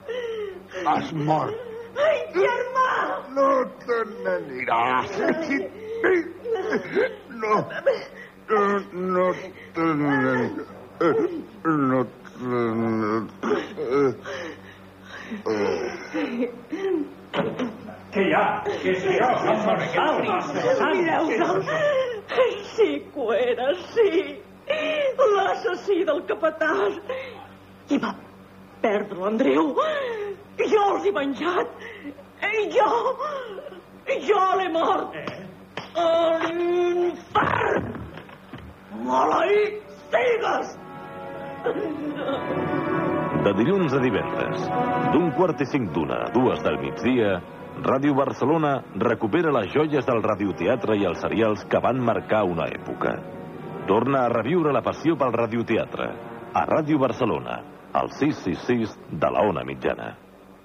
Promoció del programa que recuperava antics ràdioteatres de l'emissora